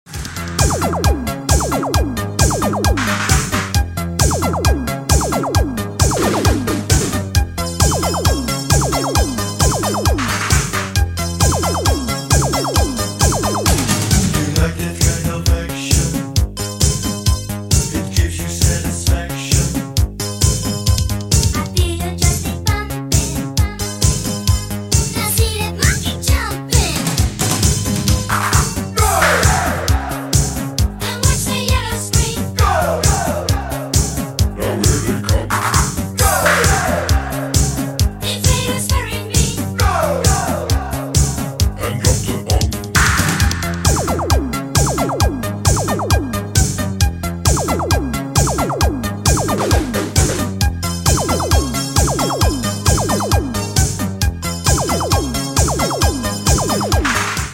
80s